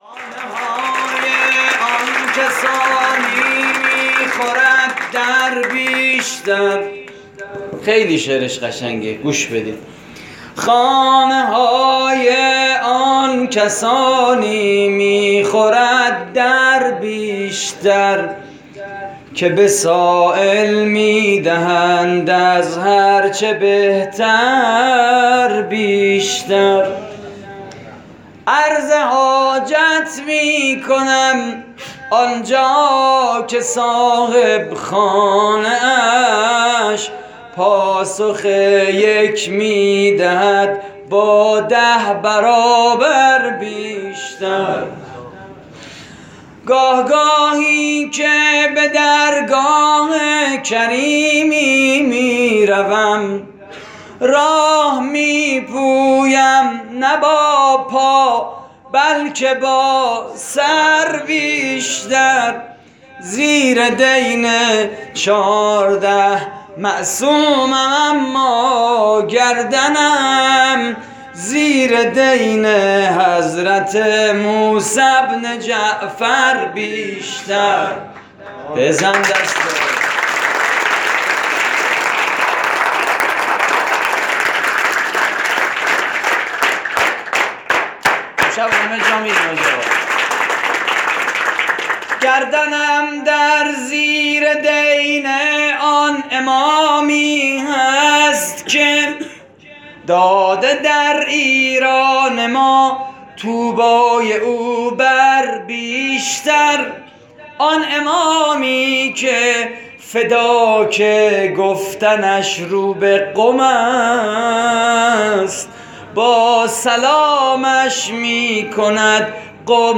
مراسم جشن ولادت امام موسی کاظم (ع) / هیئت کریم آل طاها (ع) - مشهد مقدس؛ 20 شهریور 96
مدح: زیر دین چهارده معصومم اما...؛ پخش آنلاین |